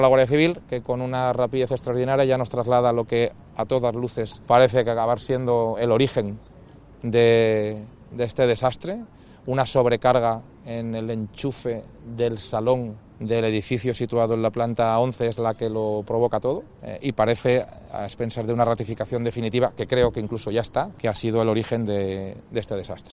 Mazón explica que las causas del incendio de La Vila fue una sobrecarga en un enchufe del salón